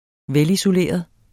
Udtale [ ˈvεlisoˈleˀʌð ]